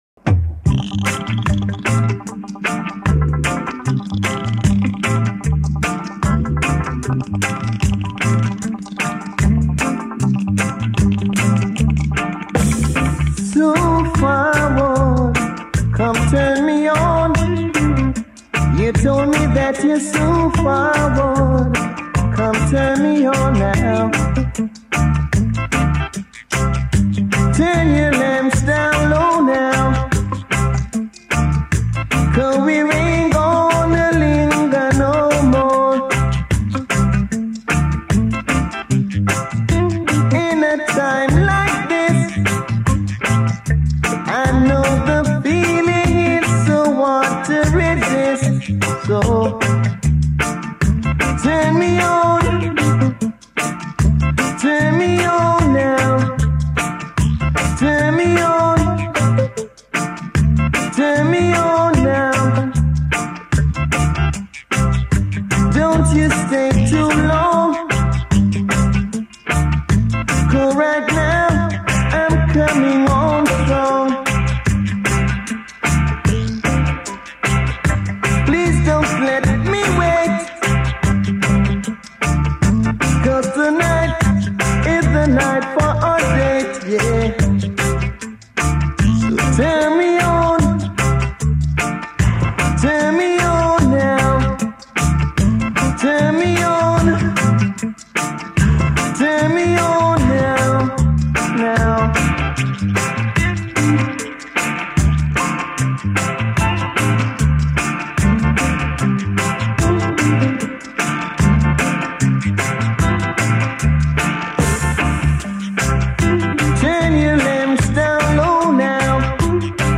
Jamaican 12″ EP